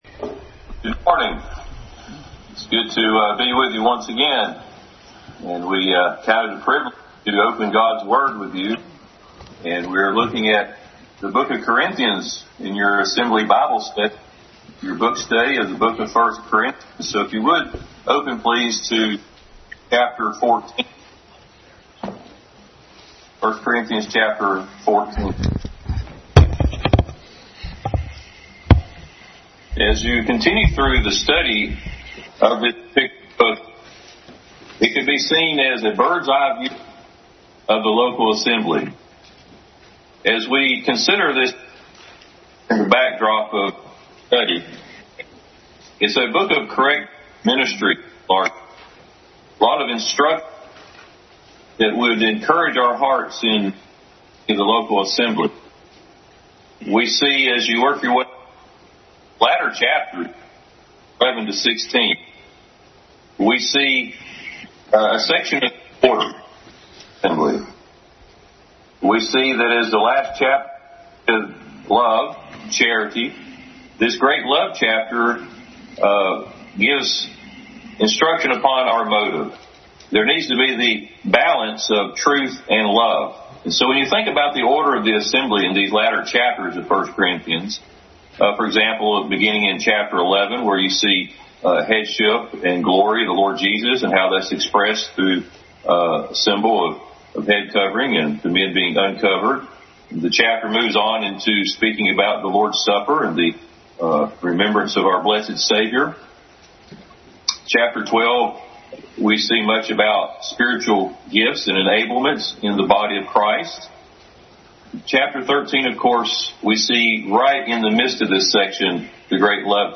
Adult Sunday School Class continued study in 1 Corinthians.